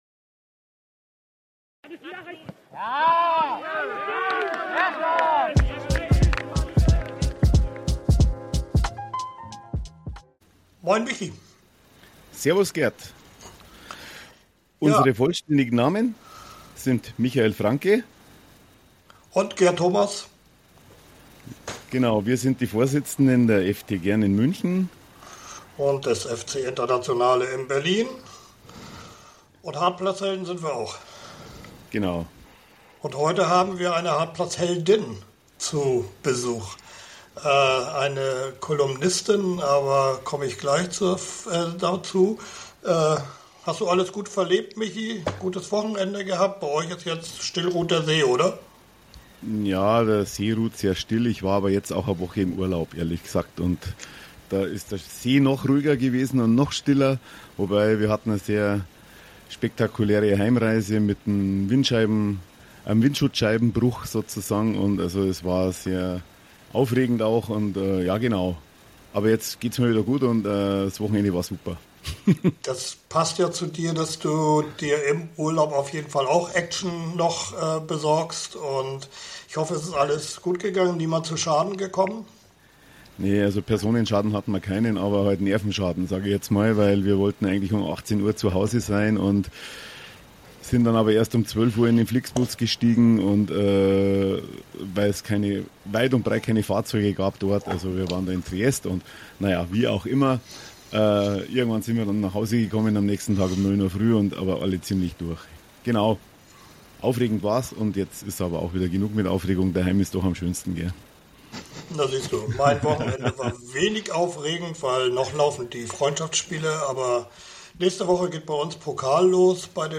Die drei diskutieren, wie wichtig gute Kommunikation zwischen Vereinen, Eltern und Ehrenamtlichen ist.